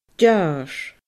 deàrrs /dʲaːRs/